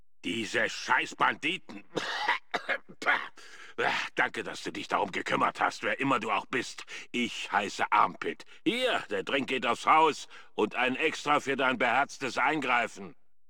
Datei:FOBOS-Dialog-Armpit-001.ogg
Kategorie:Fallout: Brotherhood of Steel: Audiodialoge Du kannst diese Datei nicht überschreiben.
FOBOS-Dialog-Armpit-001.ogg